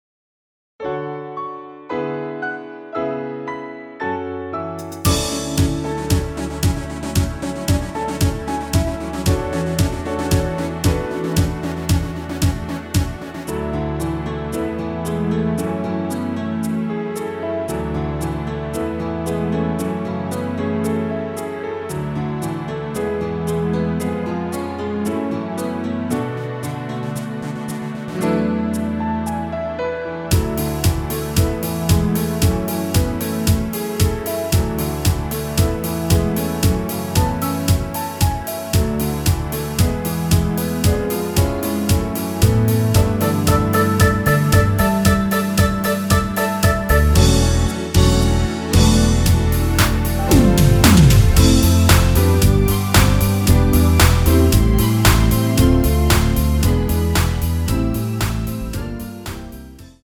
원키에서(-1)내린 MR입니다.
앞부분30초, 뒷부분30초씩 편집해서 올려 드리고 있습니다.